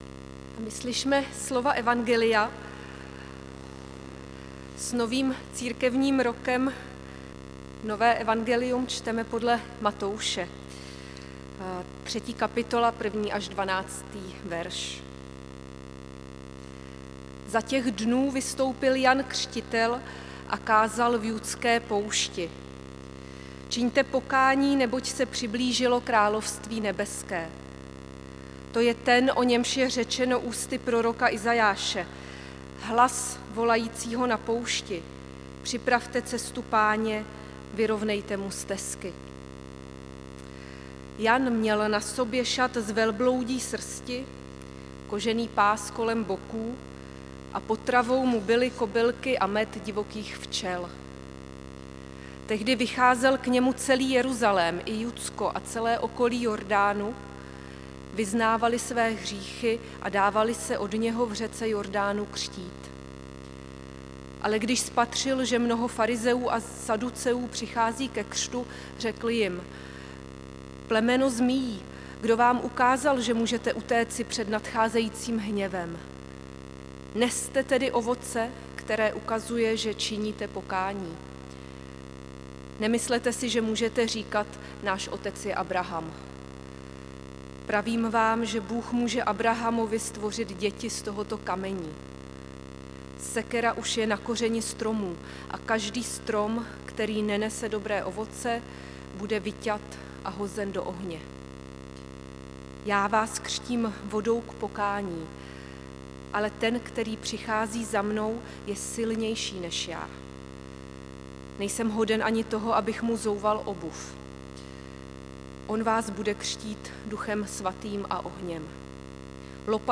2. neděle adventní 7. prosince 2025
záznam kázání Matoušovo evangelium 3, 1 – 12